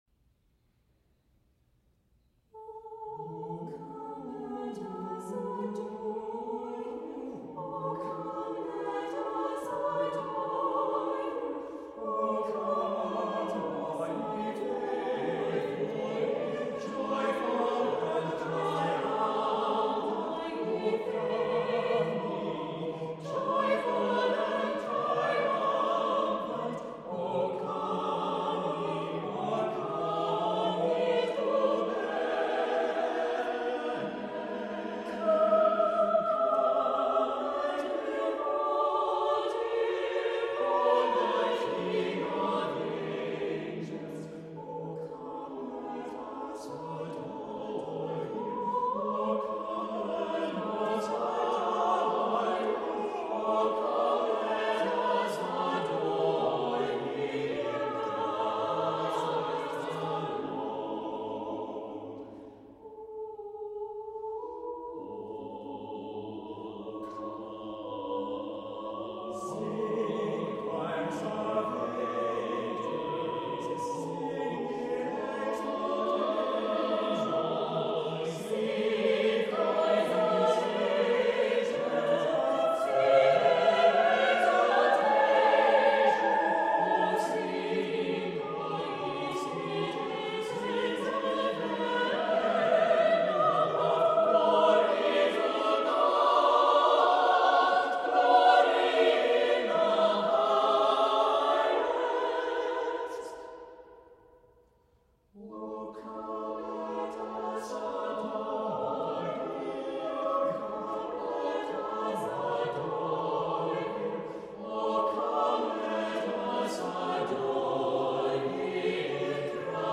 • New carol arrangements in Renaissance style